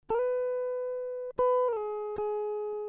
guitarra mayor mi electrica zamba